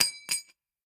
nut_impact_02.ogg